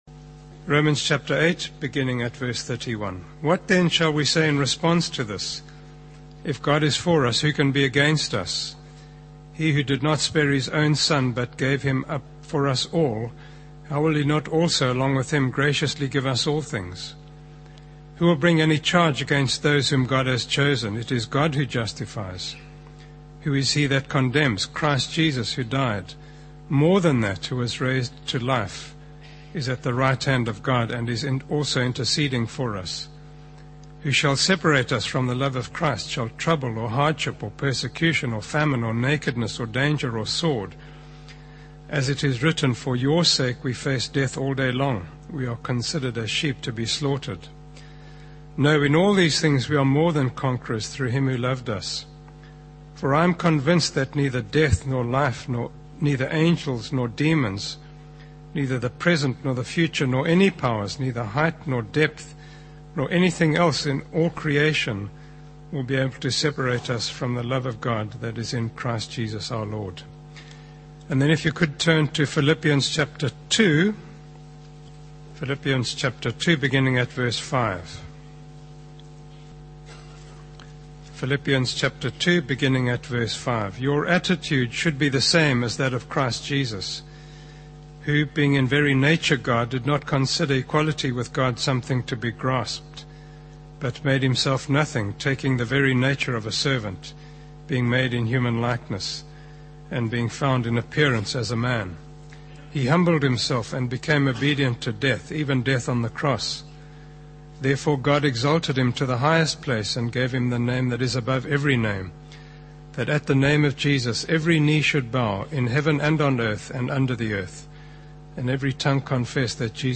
by Frank Retief | Jan 21, 2025 | Frank's Sermons (St James) | 0 comments